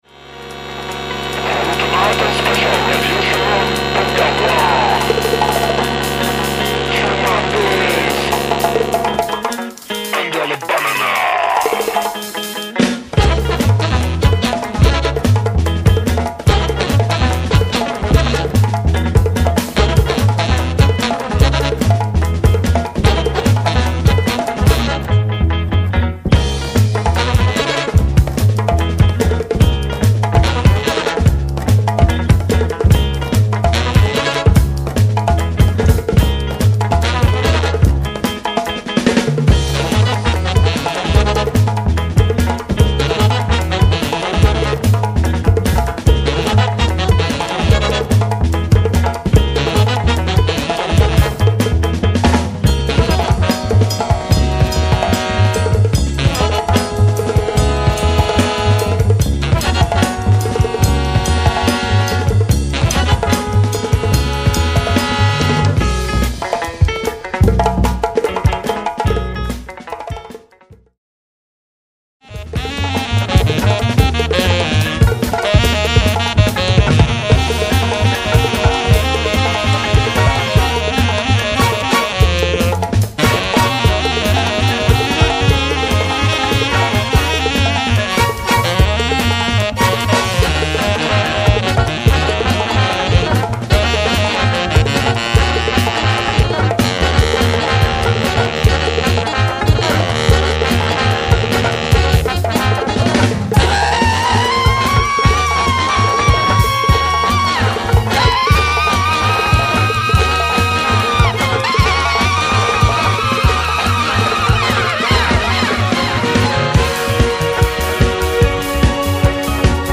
funk ethno roots
# Du groove en transe - Libération
# Energique et original - Aden/le Monde